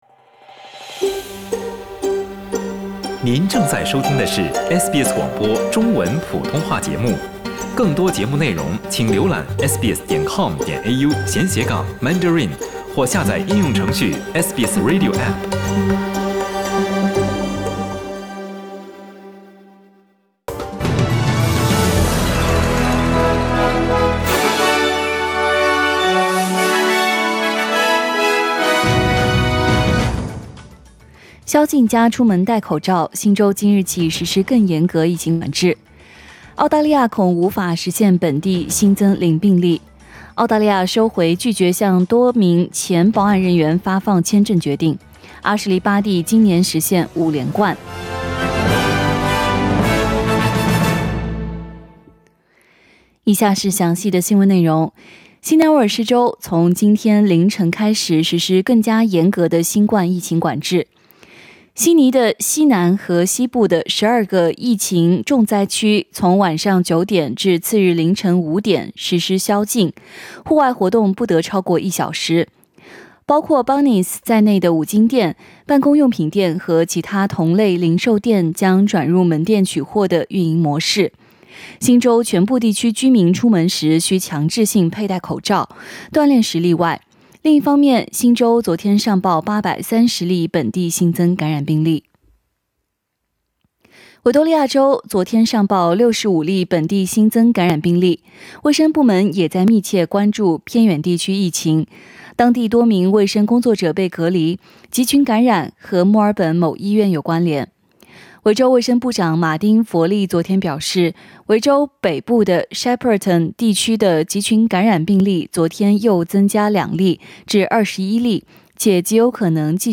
SBS早新聞 （8月23日）
SBS Mandarin morning news